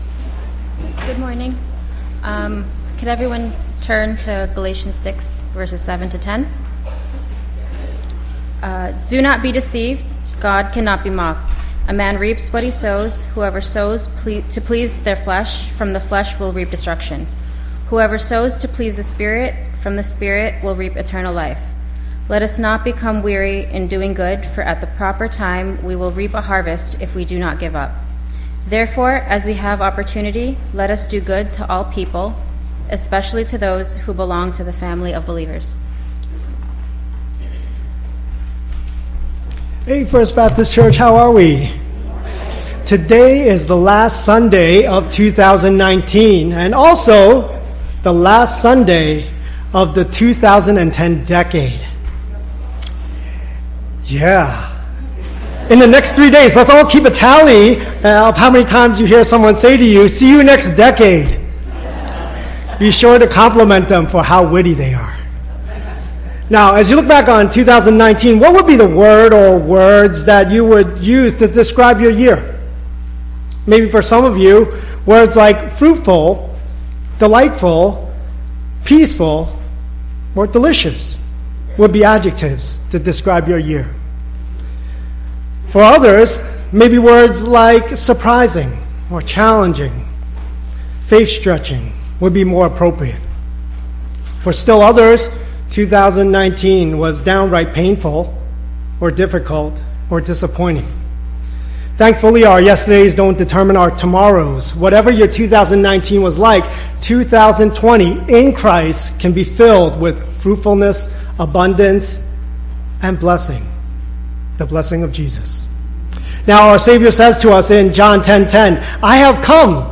English Sermons | First Baptist Church of Flushing